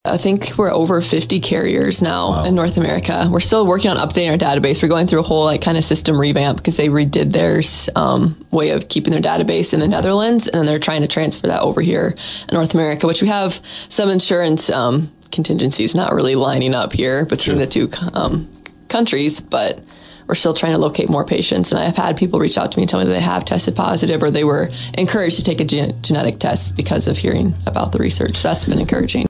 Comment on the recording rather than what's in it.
Some of the audio in this story comes from our fellow Community First Broadcasting station, KSOU’s program, “The Daily Grind.”